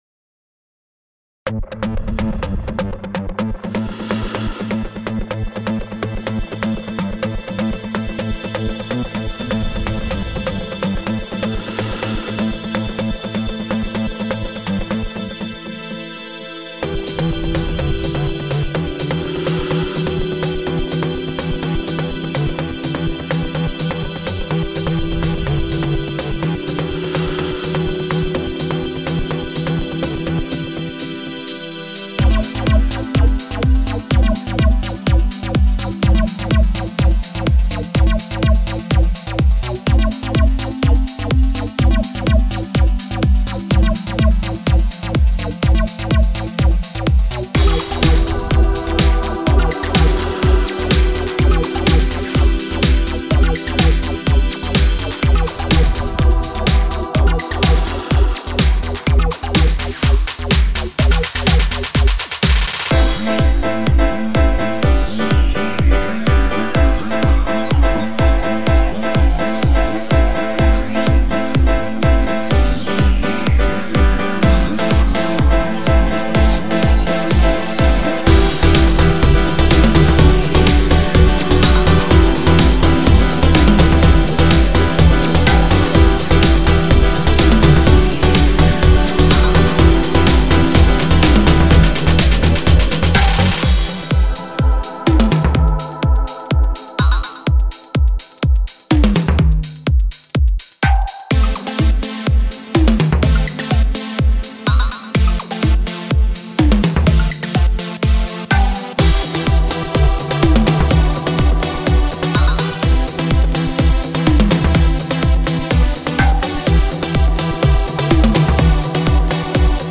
Ich mache hauptsächlich elektronische, instrumentale Musik.